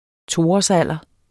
Udtale [ ˈtoɒs- ]